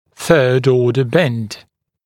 [θɜːd ‘ɔːdə bend][сё:д ‘о:дэ бэнд]изгиб третьего порядка